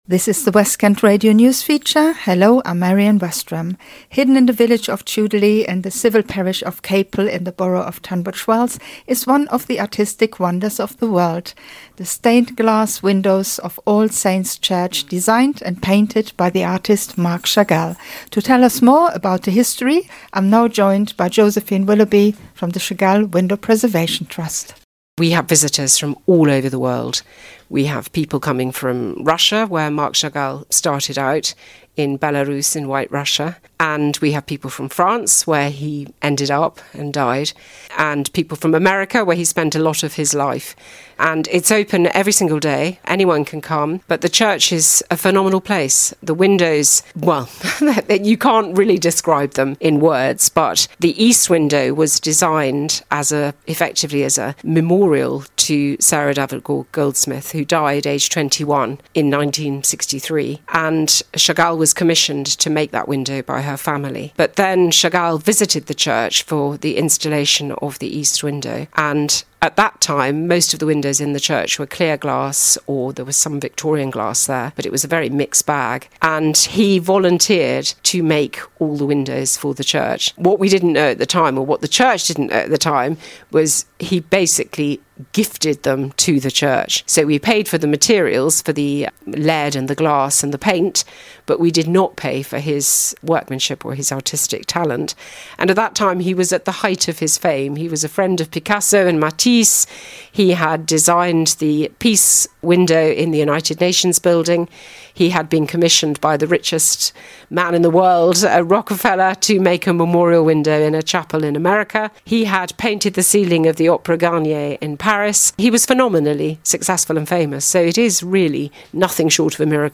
spoke to our reporter